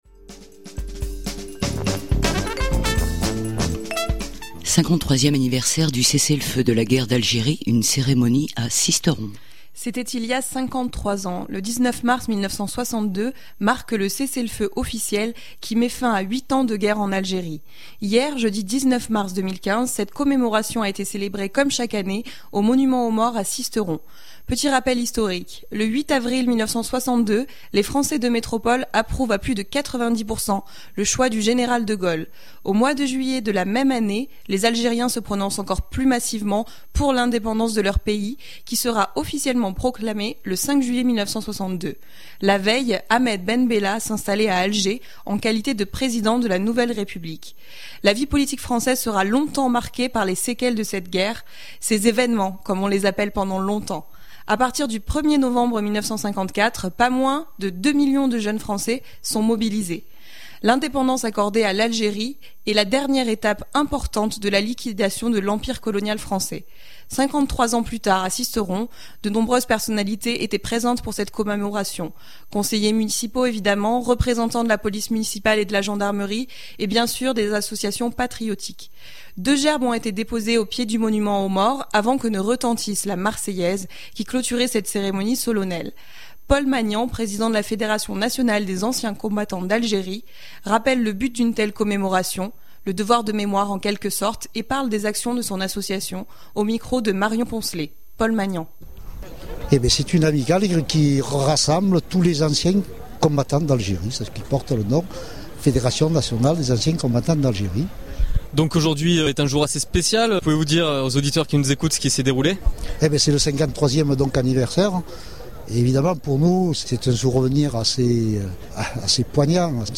Deux gerbes ont étés déposées au pied du Monuments aux Morts, avant que ne retentisse la Marseillaise qui clôturait cette cérémonie solennelle.